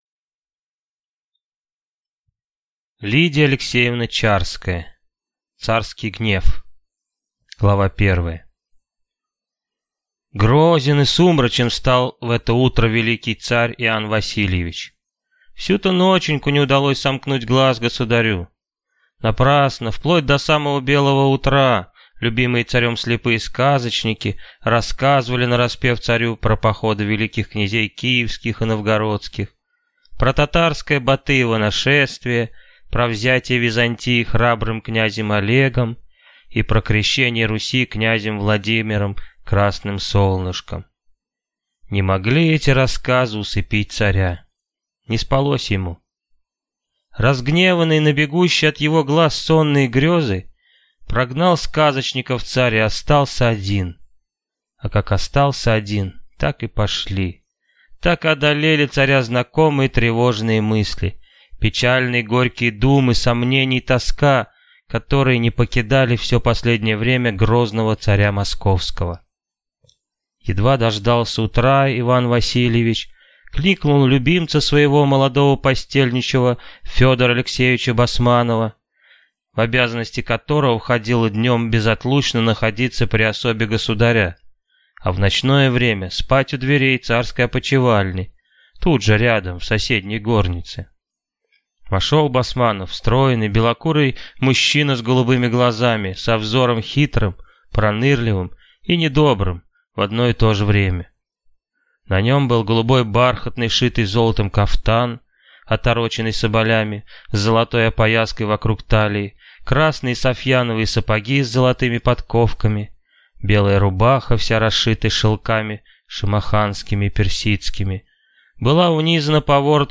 Аудиокнига Царский гнев | Библиотека аудиокниг